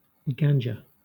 Ficheiro de áudio de pronúncia.